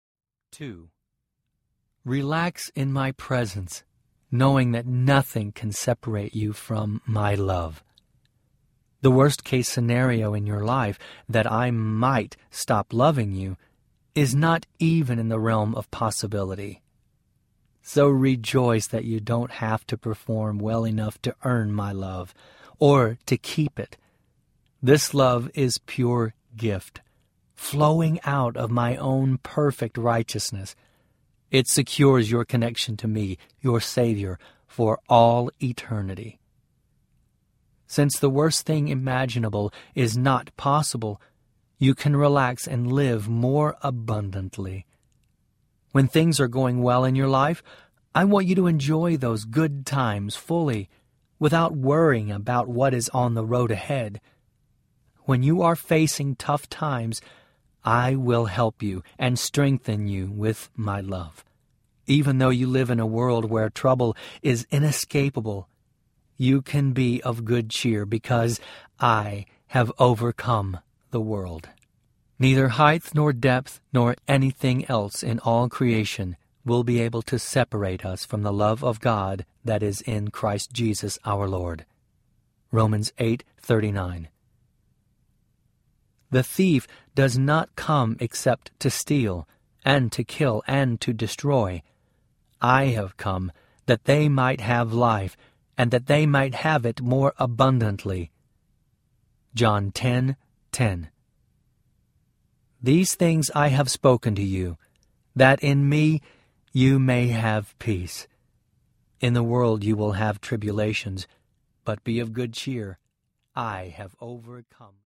Jesus Today Audiobook
5.9 Hrs. – Unabridged